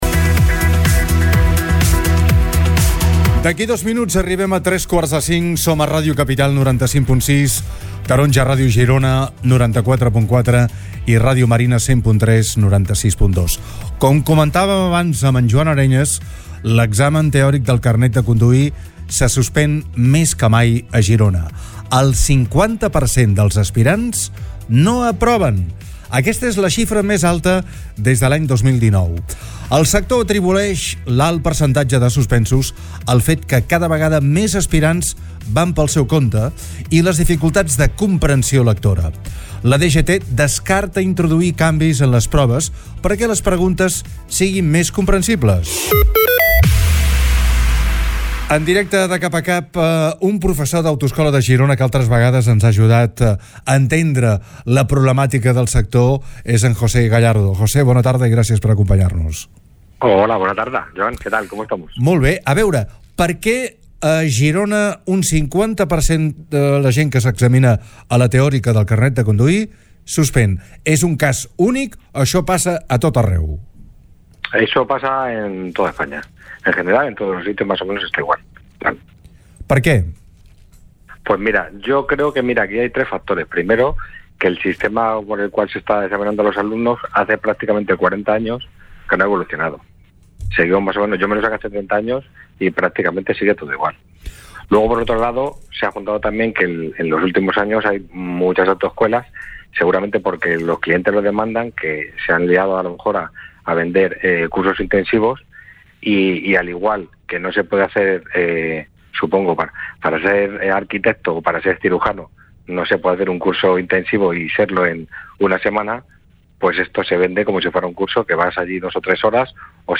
hem entrevistat